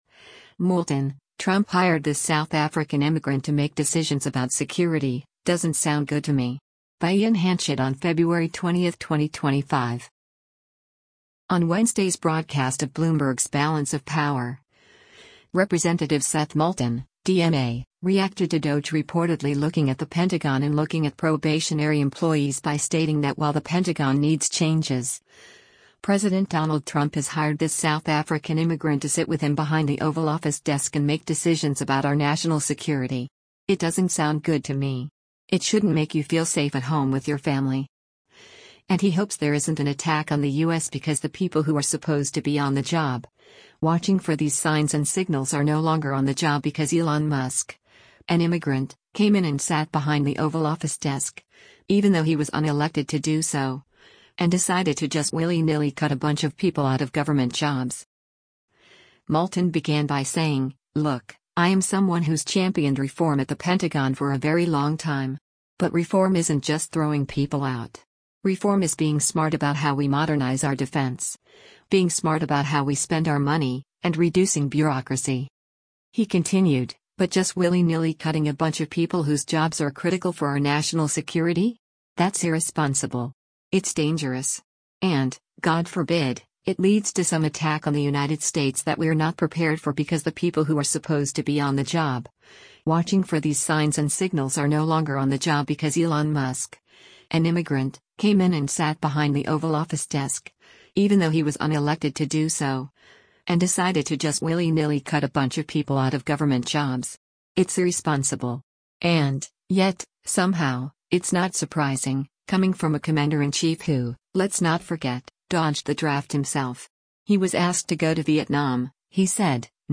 On Wednesday’s broadcast of Bloomberg’s “Balance of Power,” Rep. Seth Moulton (D-MA) reacted to DOGE reportedly looking at the Pentagon and looking at probationary employees by stating that while the Pentagon needs changes, President Donald Trump has “hired this South African immigrant to sit with him behind the Oval Office desk and make decisions about our national security.